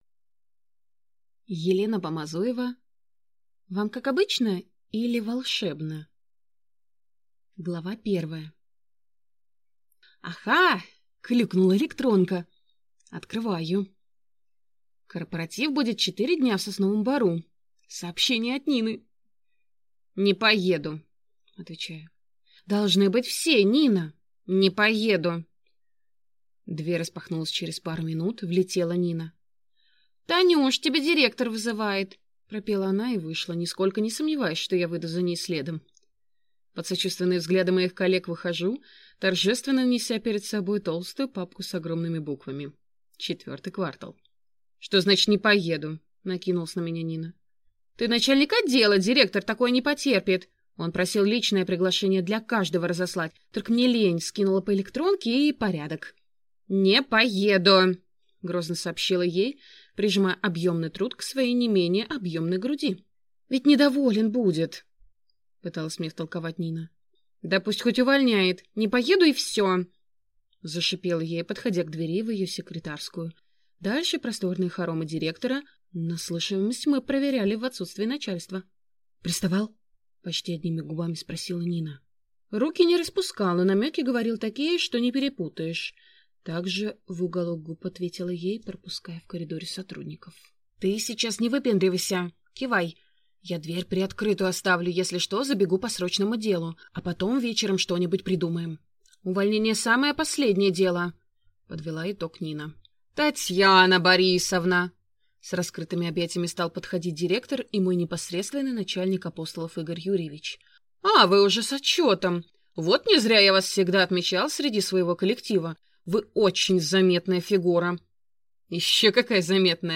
Аудиокнига Вам как обычно… или волшебно?